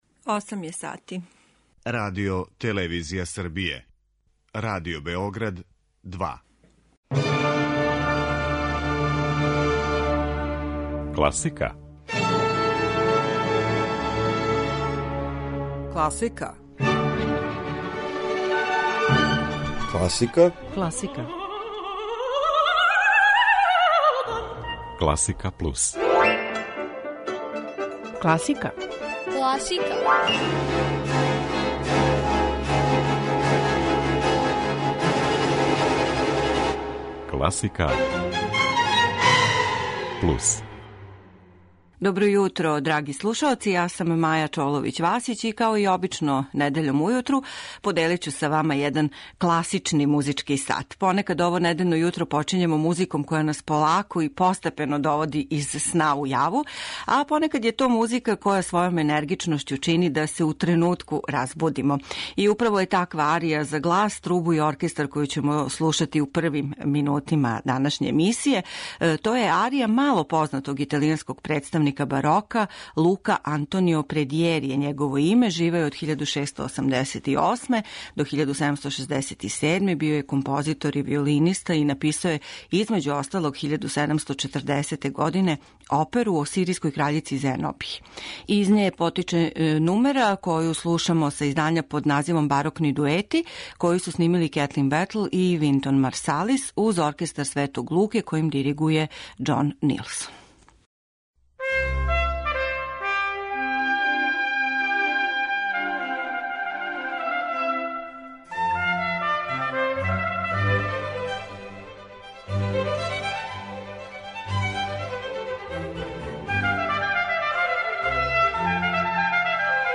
Избор класичне музике недељом ујутру...